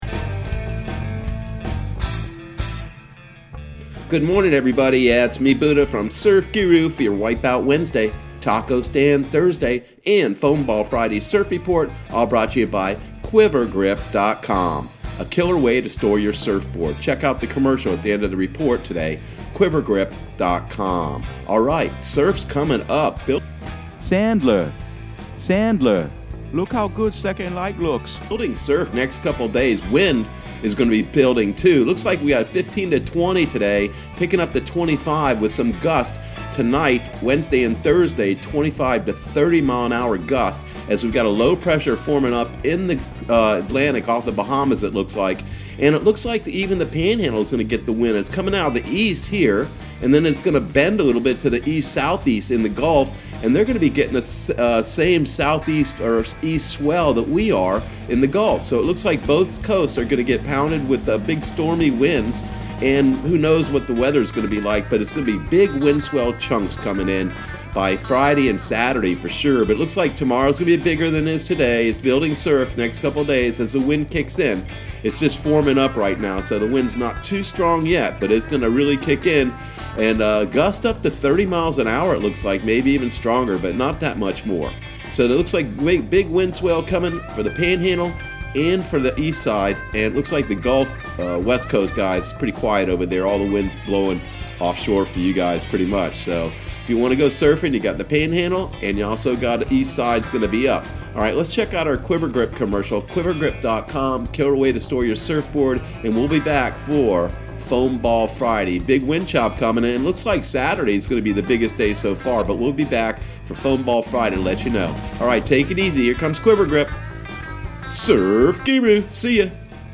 Surf Guru Surf Report and Forecast 05/13/2020 Audio surf report and surf forecast on May 13 for Central Florida and the Southeast.